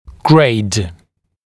[greɪd][грэйд]классифицировать, сортировать, ранжировать